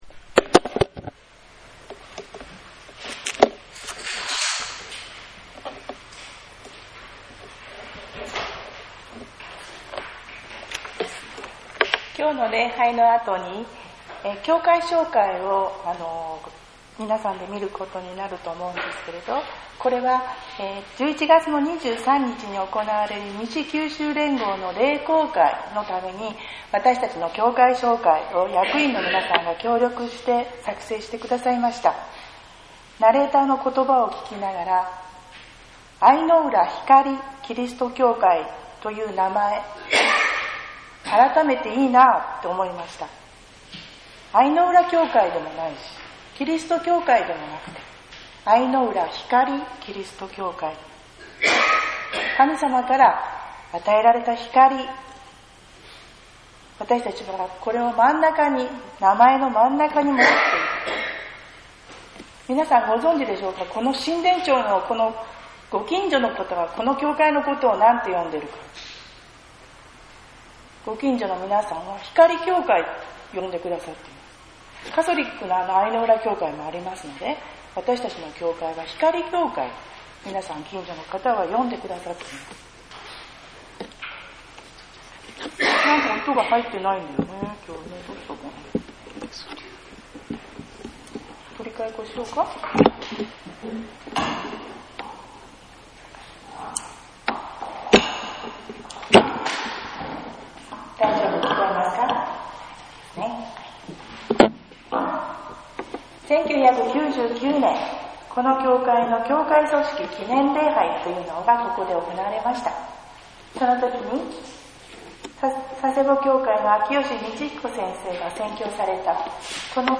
弟子たちの中に 2020年10月11日 主日礼拝